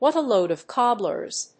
アクセントWhàt a lóad of (óld) cóbblers!＝Cobblers!